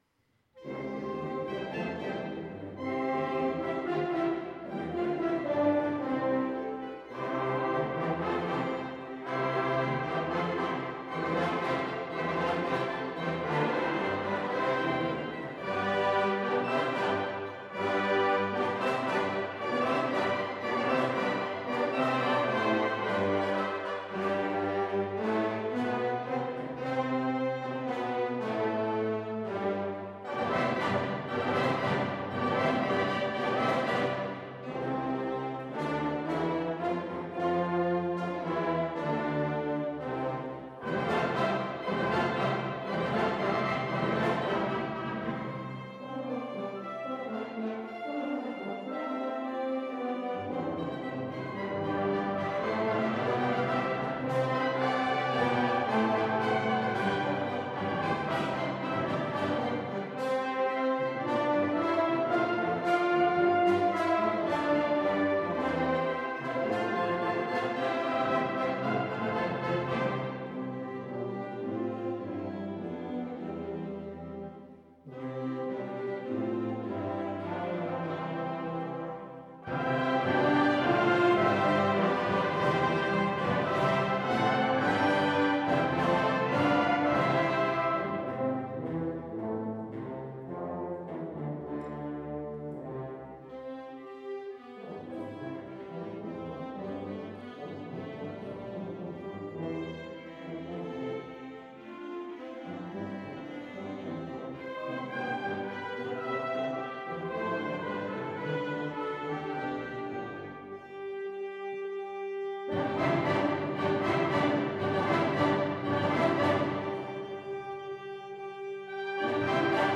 Mit weihnachtlichen Klängen füllten wir am dritten Adventssonntag die Hückeswagener Paulus Kirche.
Besonders die Klarinetten waren gefragt und hatten zahlreiche Registerproben durchgeführt, um Gegenmelodien und schnelle Läufe meistern zu können.
Da auch die Zuhörer begeistert waren, gab es „Halleluja“ am Ende noch mal als Zugabe, die mit viel Applaus belohnt wurde.
Ein paar Stücke haben wir auf dem Konzert aufgenommen und unten als Hörprobe für euch zur Verfügung gestellt.